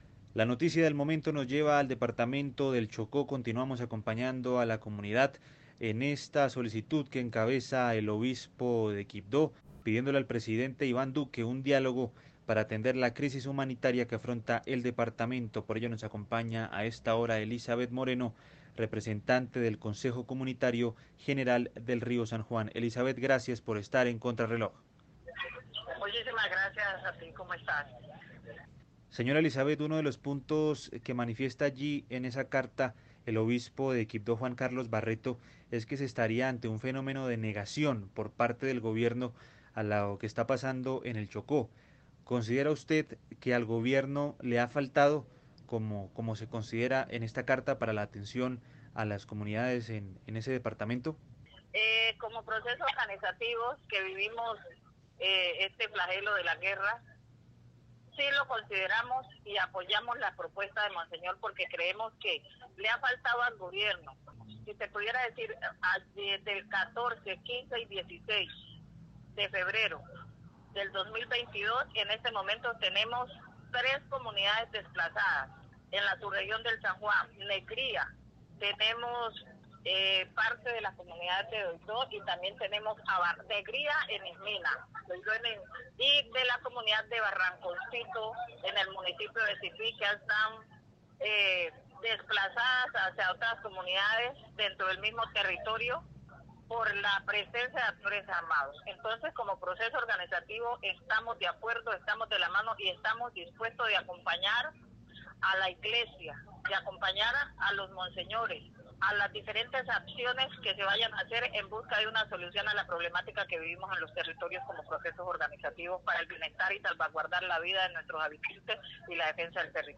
En entrevista con Contrarreloj